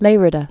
(lārĭ-də, lĕrē-thä) or Llei·da (lyĕthə)